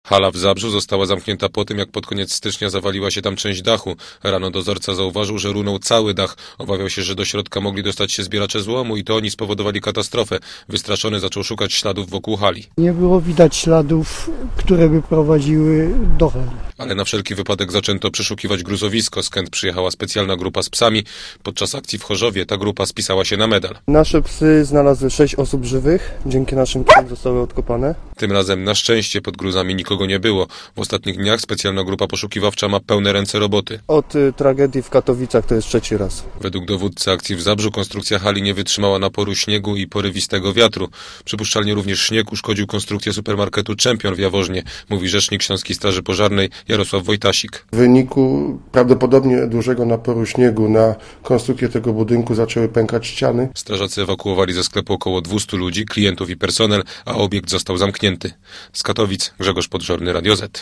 Relacja reportera Radia ZET Fragment dachu dawnej hali produkcyjnej, obecnie nieużytkowanej, zawalił się w Tarnowskich Górach.